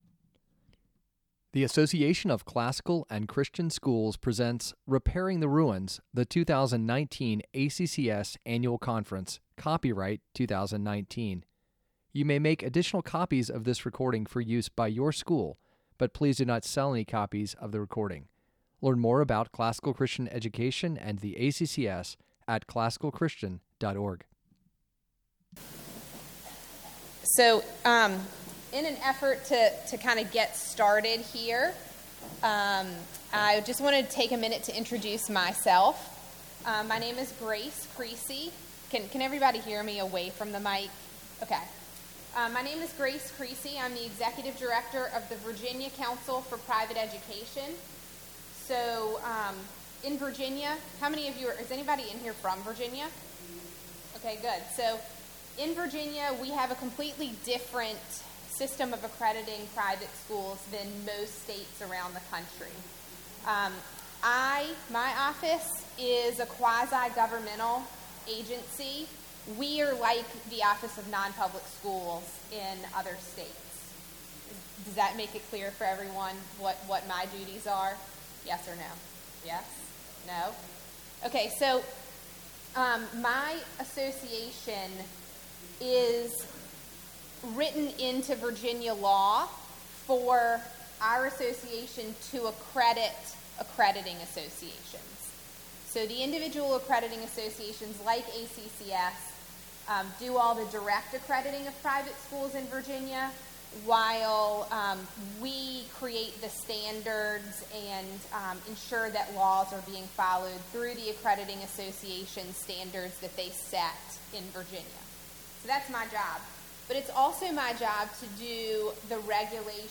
2019 Workshop Talk | 59:10 | All Grade Levels, Leadership & Strategic, Operations & Facilities
Additional Materials The Association of Classical & Christian Schools presents Repairing the Ruins, the ACCS annual conference, copyright ACCS.